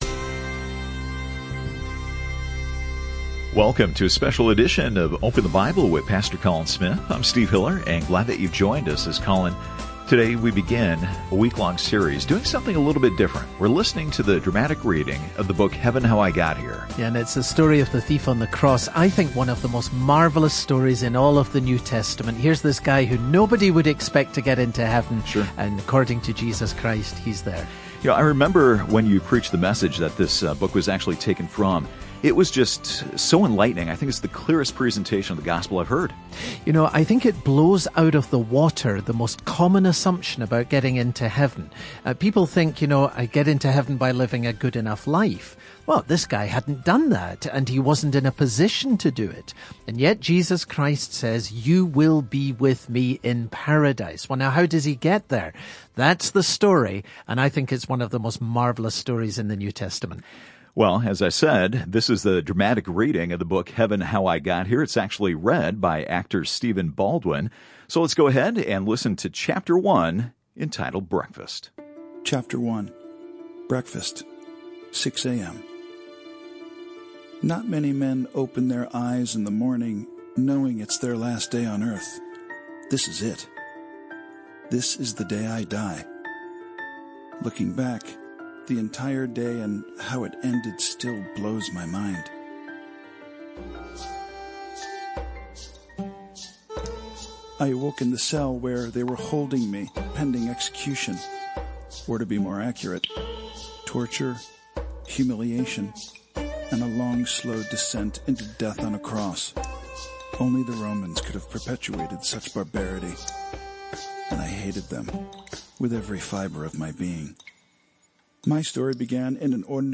This radio broadcast features narration by actor Stephen Baldwin.